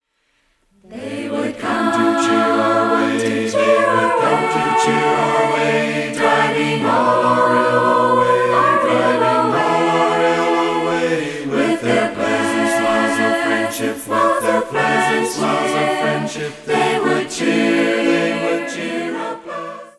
A cappella mixed group sings many beautiful hymns.